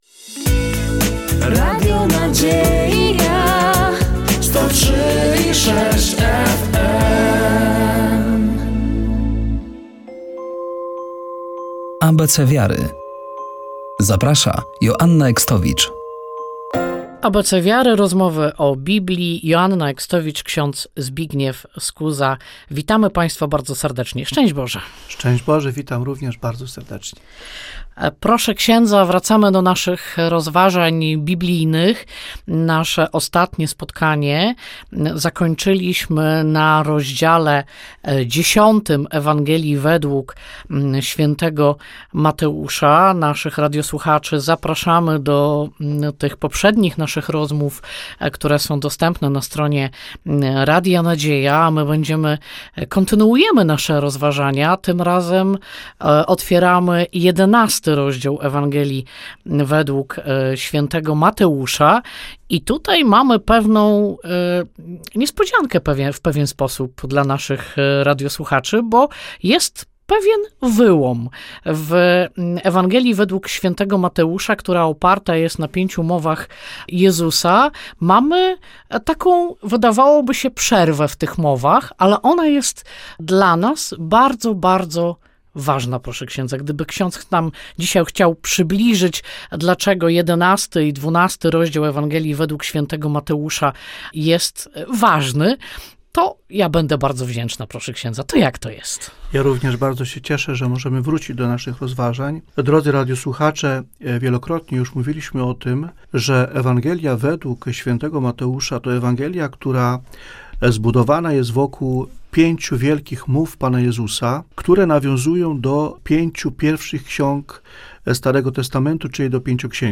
Wracamy do rozmów o Biblii i kontynuujemy rozważania nad Ewangelią wg św. Mateusza. Tym razem zajmiemy się 11 i 12 rozdziałem Ewangelii św. Mateusza, w której to Jezus wskazuje na swoją tożsamość.